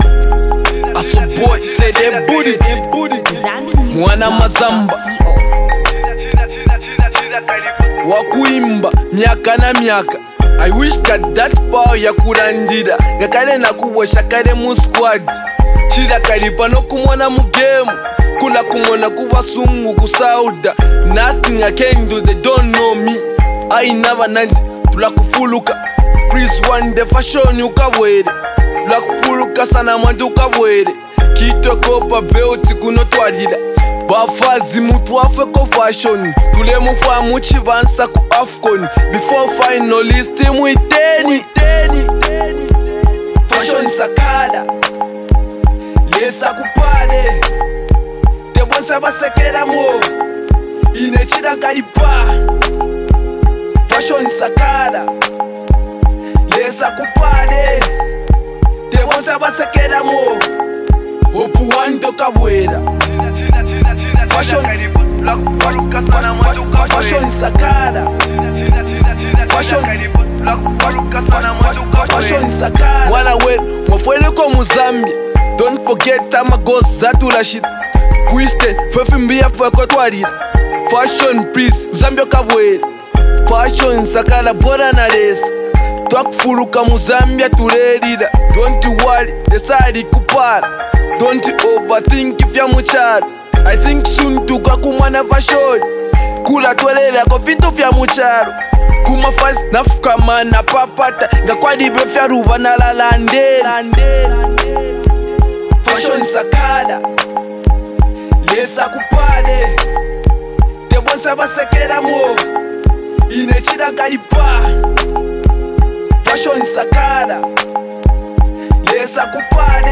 Blending Afrobeat, street vibes, and motivational undertones